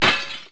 sndBreak = LoadSound("TVSmash.wav")    ;Sound played when TV is smashed
TVsmash.wav